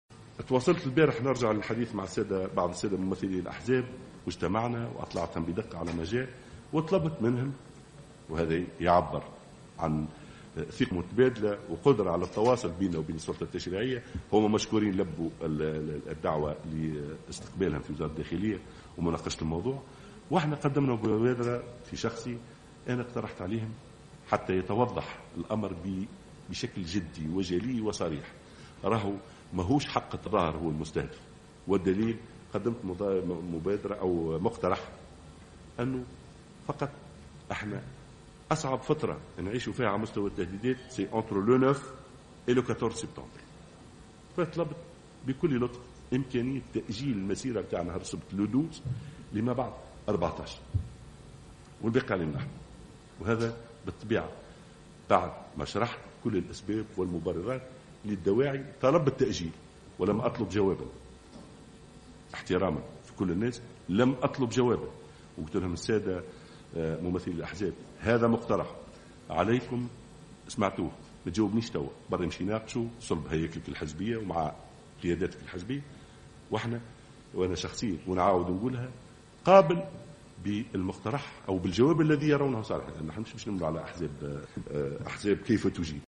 قال وزير الداخلية،محمد ناجم الغرسلي خلال ندوة صحفية اليوم الخميس إنه طلب من الأحزاب المعارضة تأجيل مسيرة 12 سبتمبر 2015 .